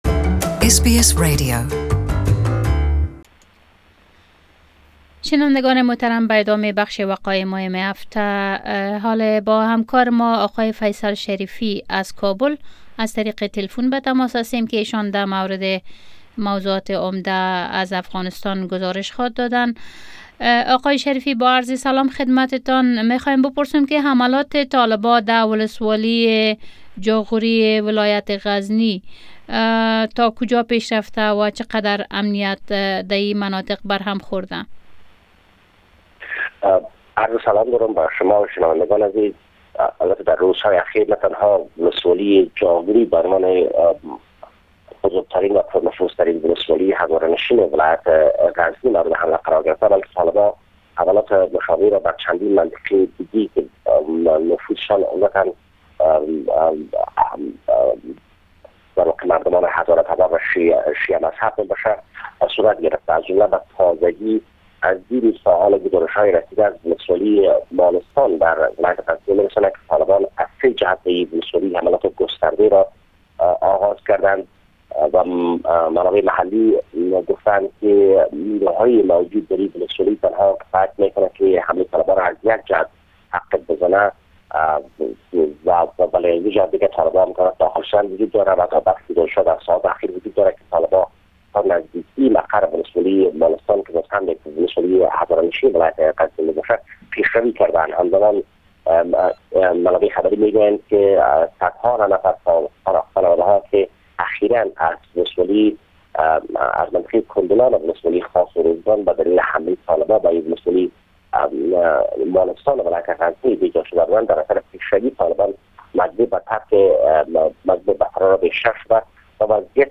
Report from Kabul 10\11\2018